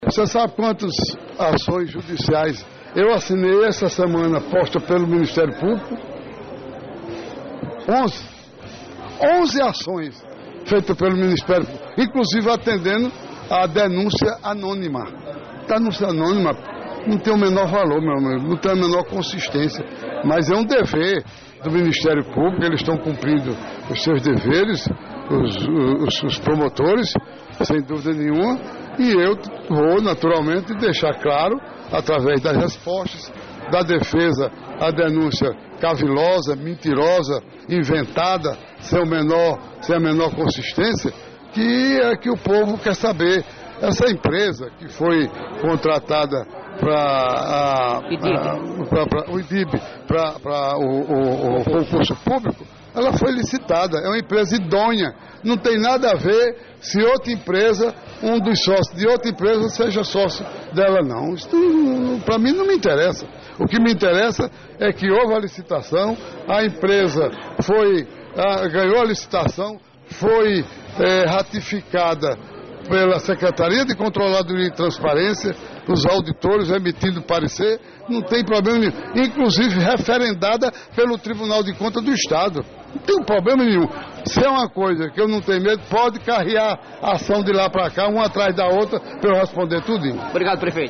Em entrevista na tarde desta quinta- feira (21) ao programa Rádio Vivo da Alto Piranhas disse que tranquilo e deixo claro que a empresa é idônea.